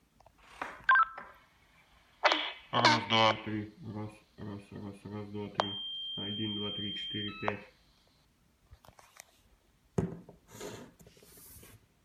Передача с DM-1801